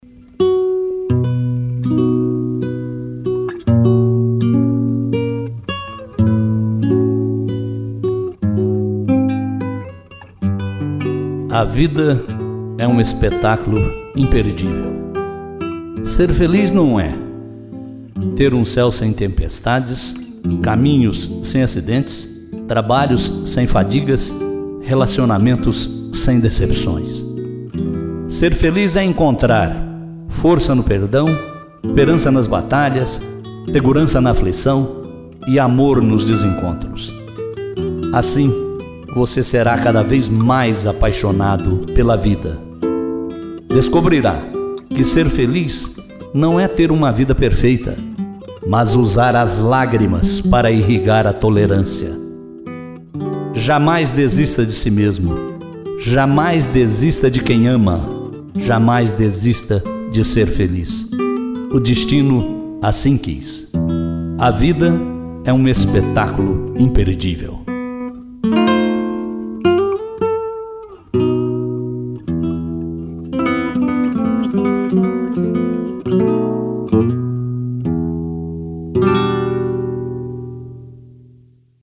interpretação